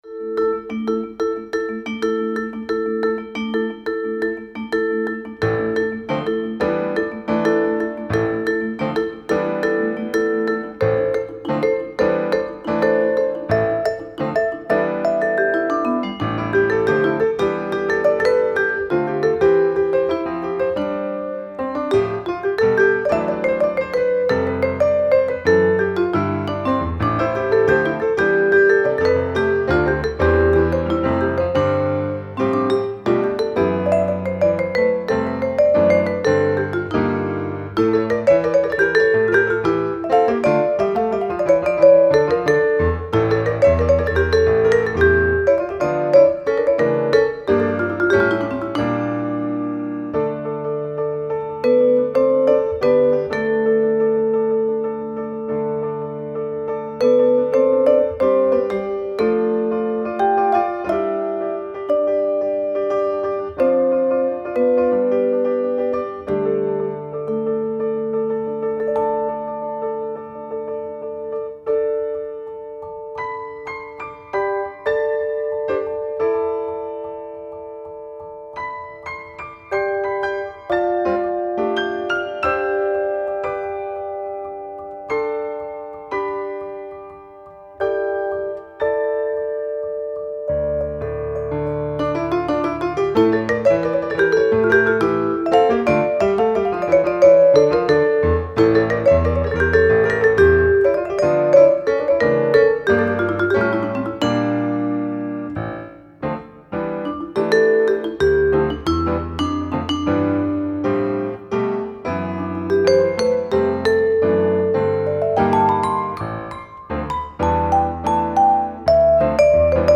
melodiös, mit Orientalischem Touch